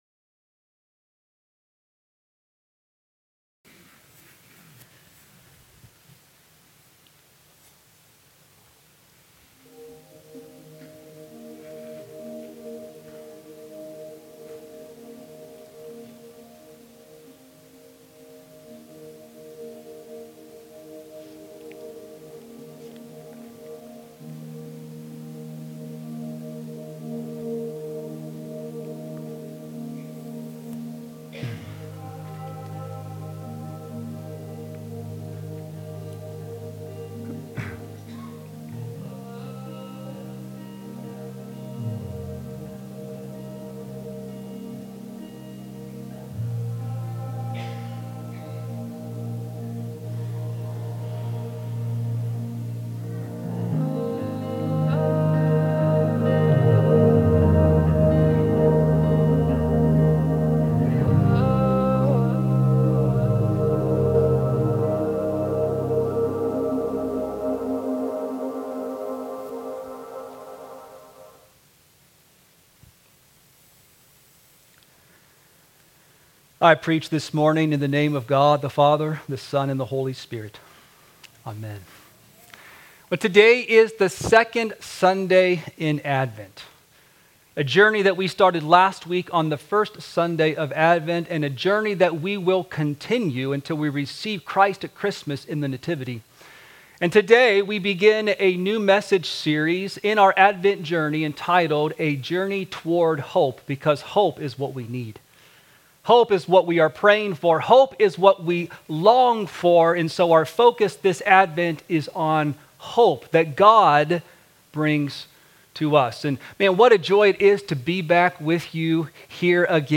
Sermons – Desert Hope Lutheran Church